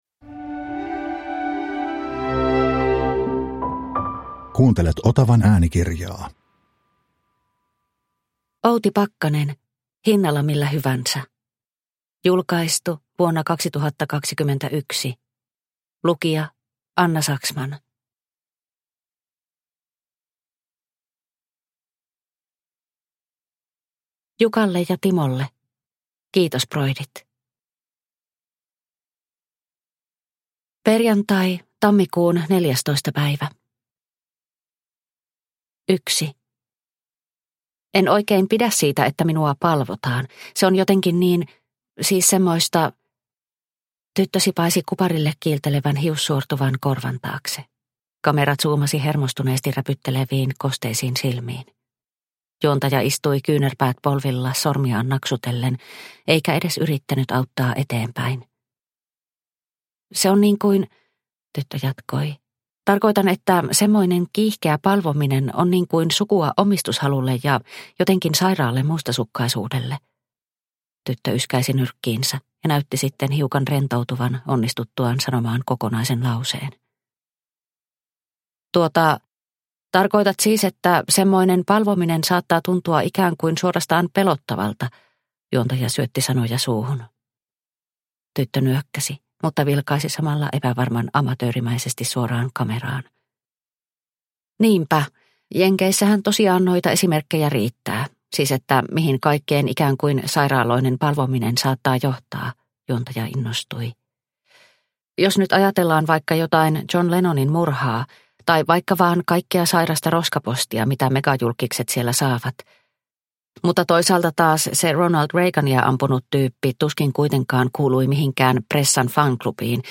Hinnalla millä hyvänsä (ljudbok) av Outi Pakkanen